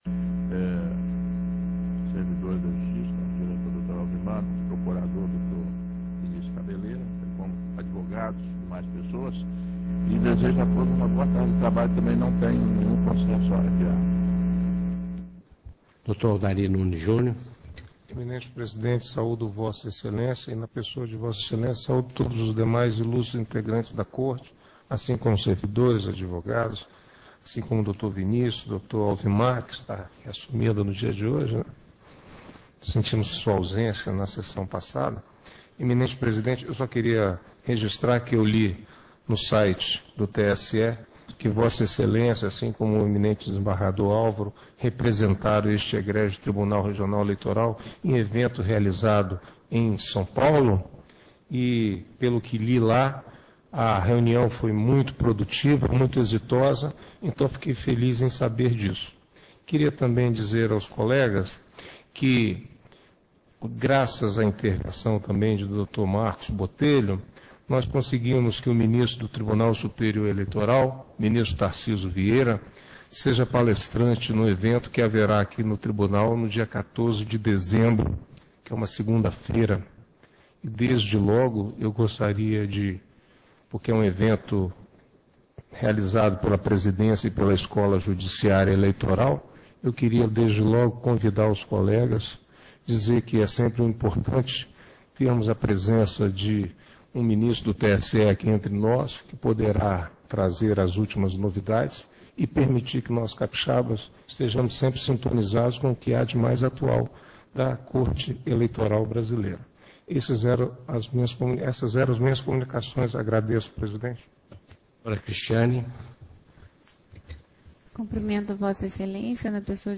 TRE-ES sessão do dia 09/11/15